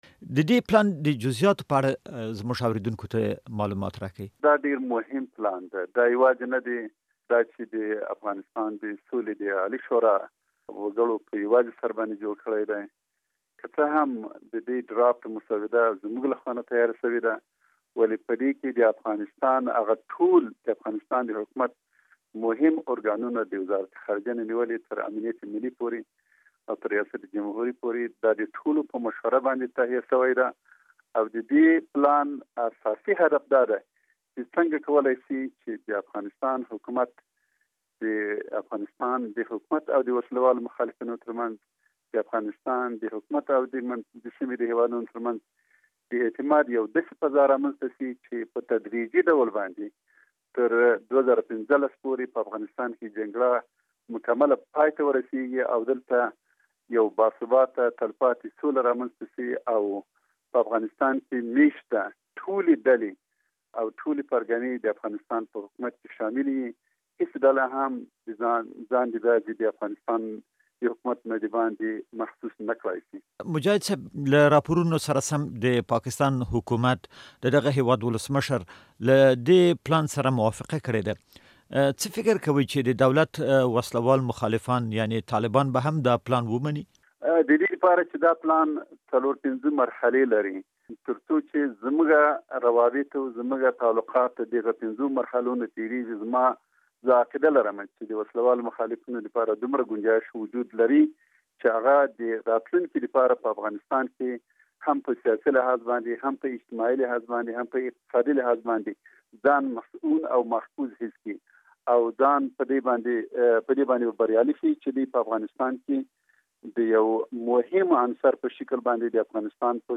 له عبدالحکيم مجاهد سره مرکه